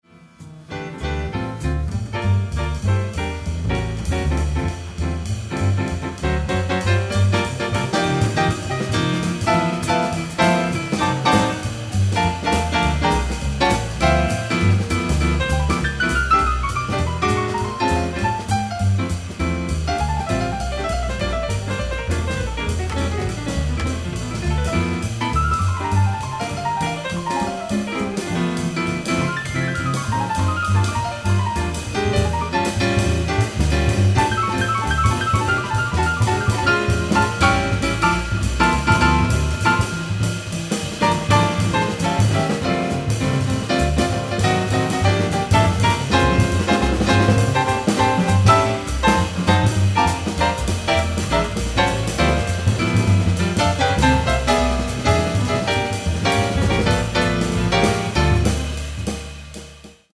piano
acoustic bass
drums